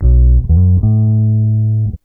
BASS 26.wav